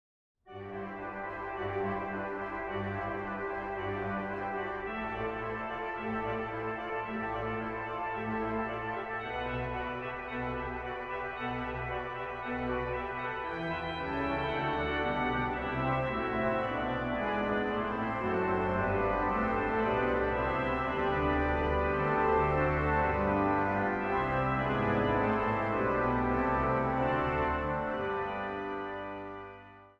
orgel
vleugel
Zang | Mannenkoor